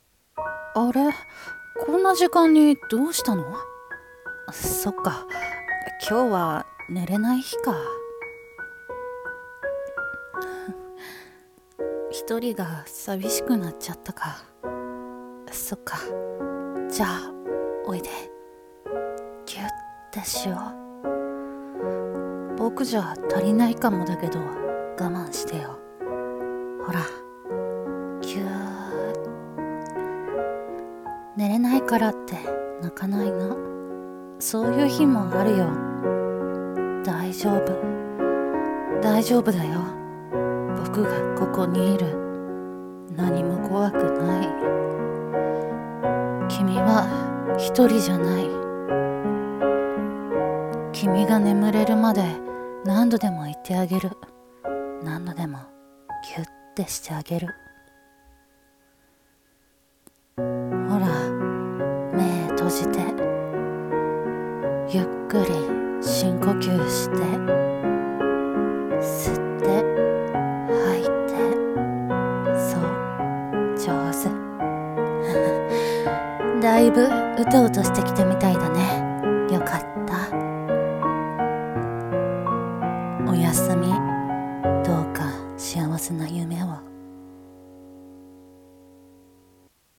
眠れない君へ 朗読